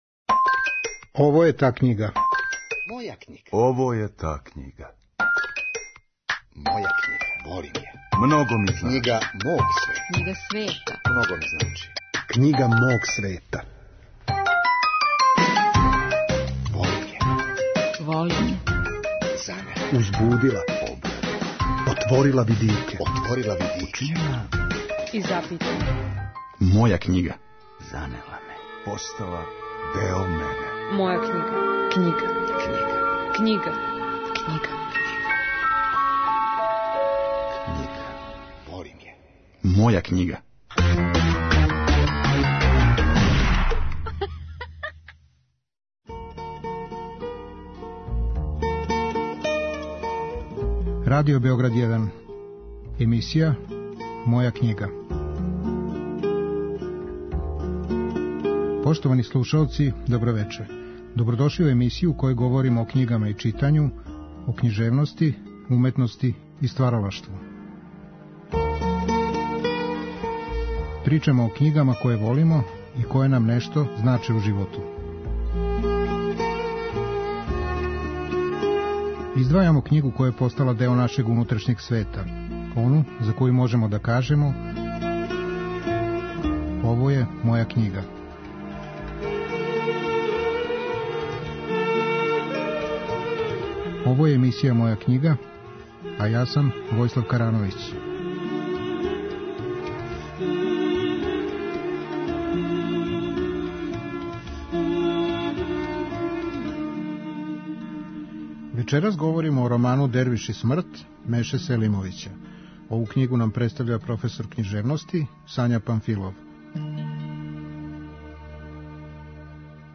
професор књижевности, говори о томе зашто воли роман ''Дервиш и смрт'' Меше Селимовића.